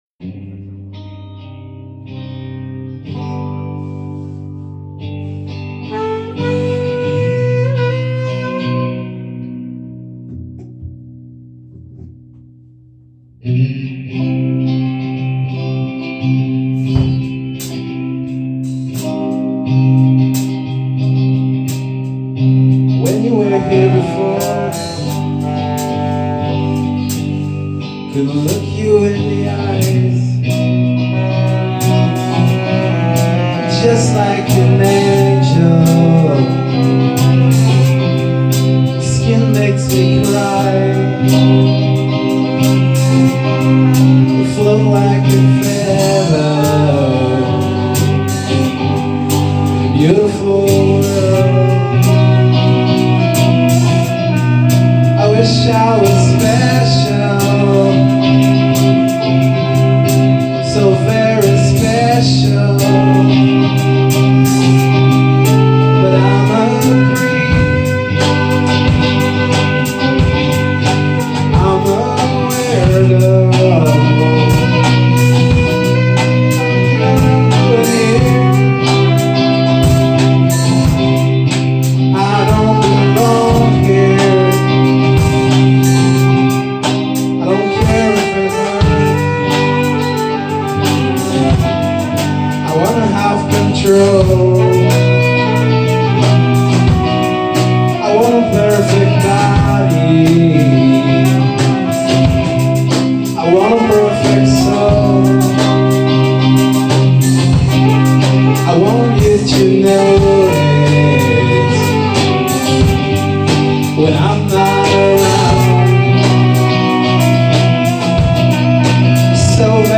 where: Chez BAM
Cover